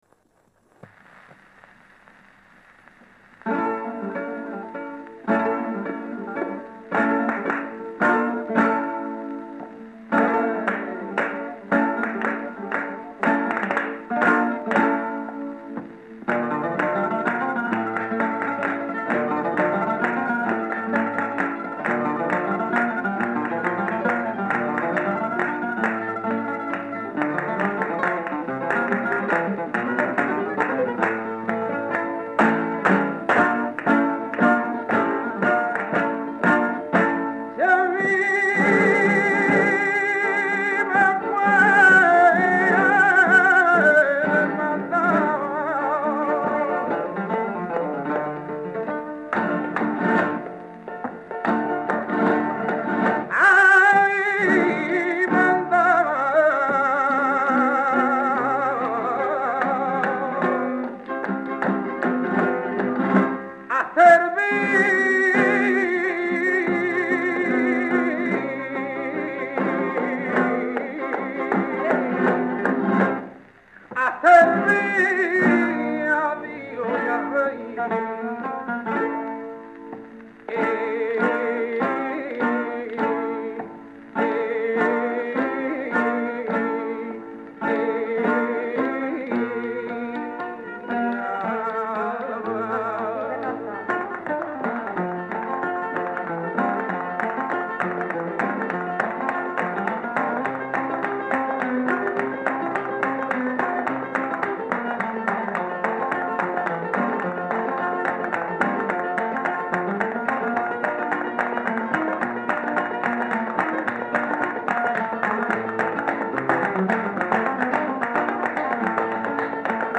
These two sides were probably recorded around 1950 .